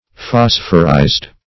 Phosphorized \Phos"phor*ized\, a. Containing, or impregnated with, phosphorus.